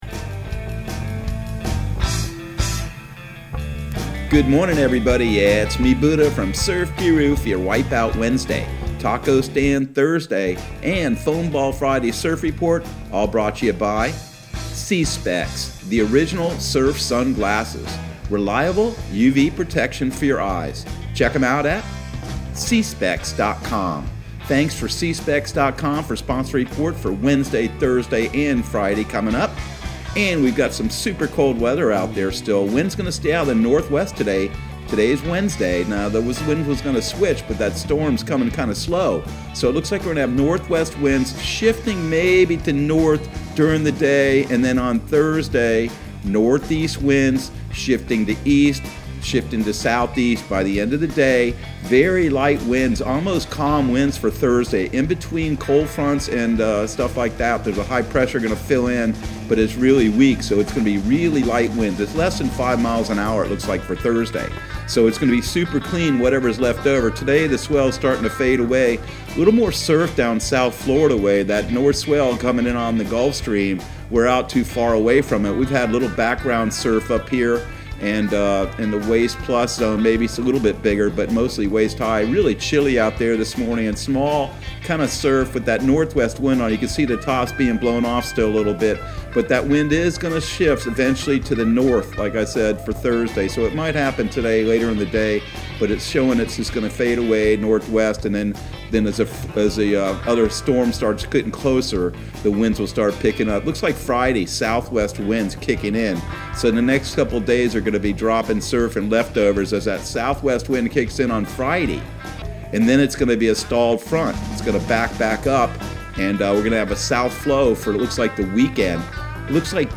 Surf Guru Surf Report and Forecast 02/03/2021 Audio surf report and surf forecast on February 03 for Central Florida and the Southeast.